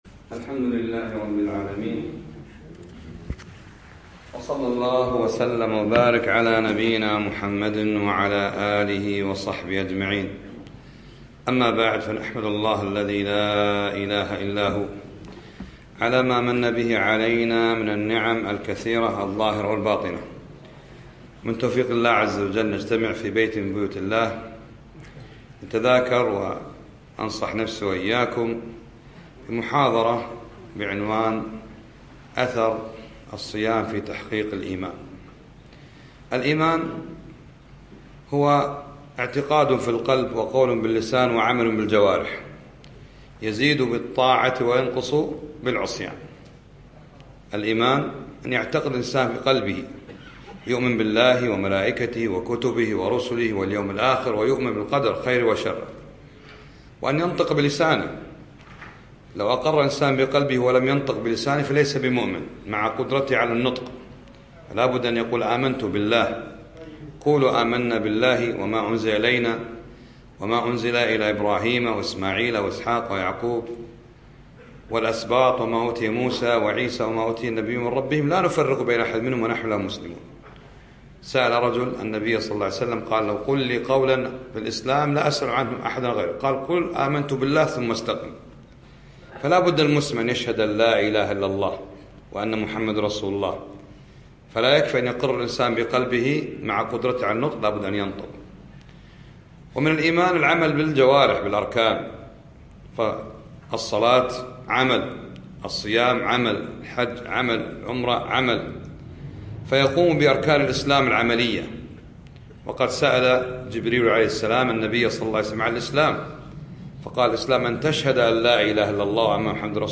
يوم الأربعاء 25 شعبان 1437هـ الموافق 2 6 2016م في مسجد طيبة النصر الله صباح السالم